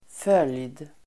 Uttal: [föl:jd]